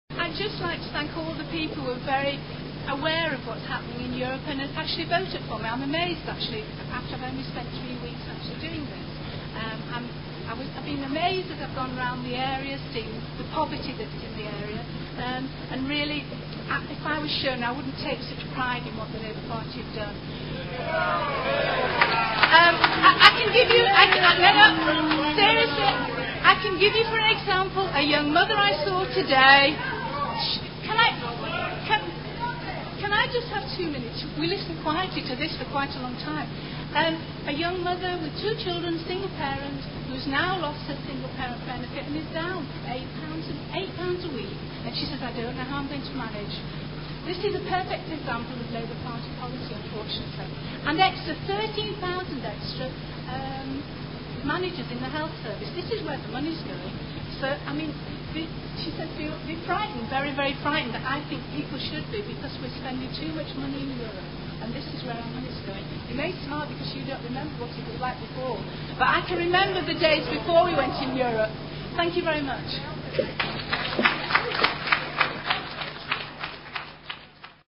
THE COUNT & DECLARATION AUDIO FOR GREAT GRIMSBY AND CLEETHORPES THE AUDITORIUM, GRIMSBY STARTING 10PM 7TH JUNE 2001 The Count of the voting papers starts immediately the polling booths close on the day of the election.